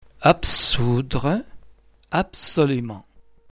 | the [b] under the influence of the neighbouring [s] is pronounced [p]